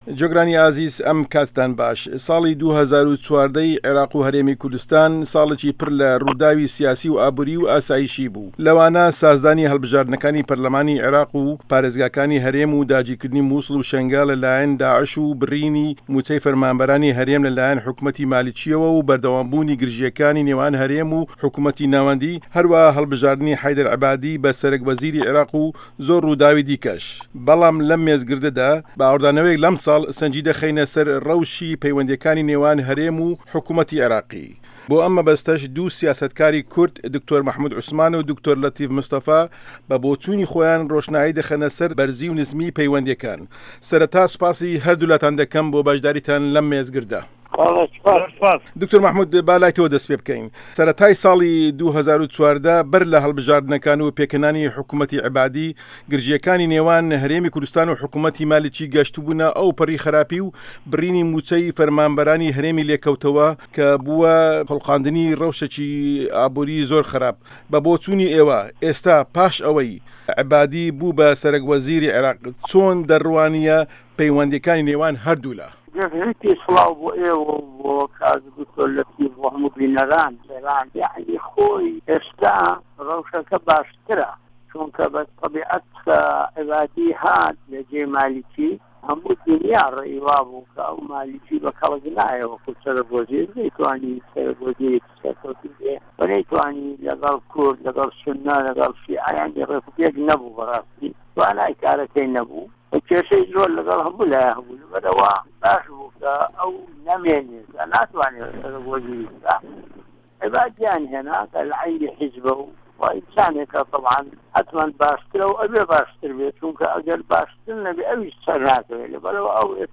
مێزگرد: عێراق و هه‌رێمی کوردسـتان له‌ ساڵی 2014 دا